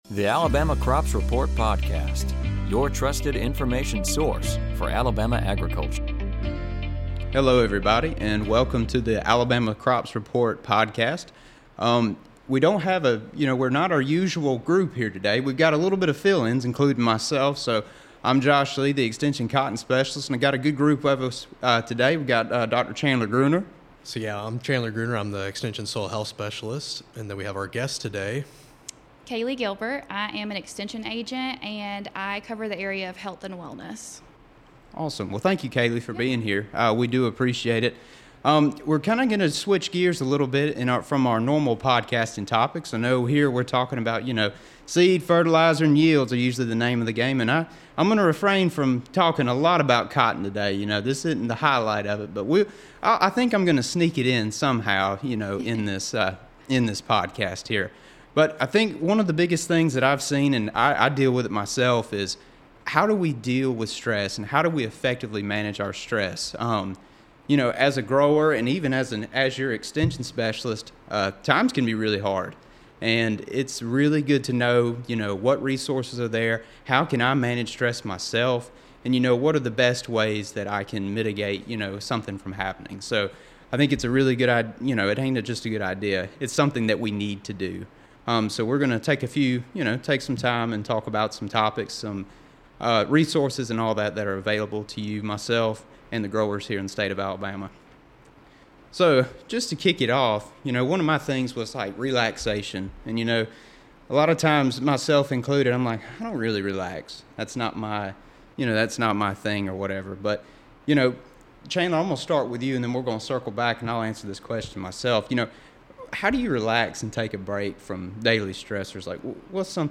On this episode, the group talks about the importance of managing stress and provides tips and resources for the agricultural industry.